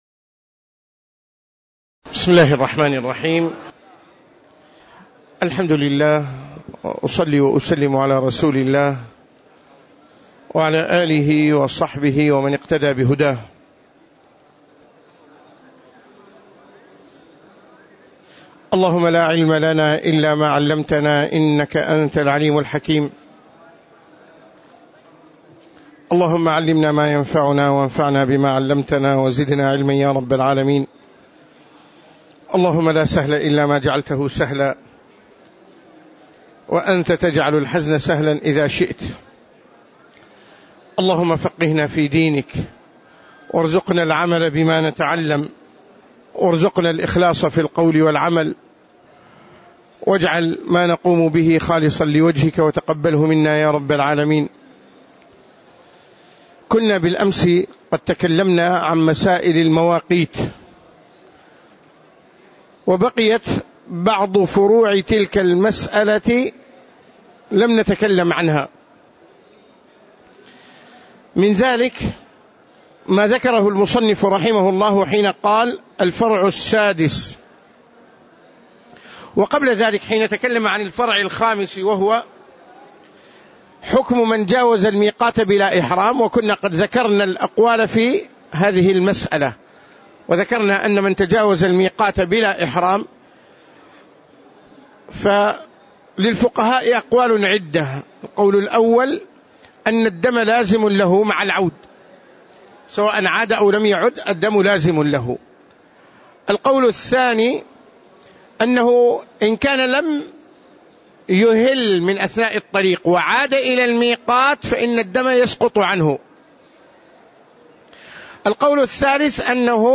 تاريخ النشر ١٠ ذو القعدة ١٤٣٧ هـ المكان: المسجد النبوي الشيخ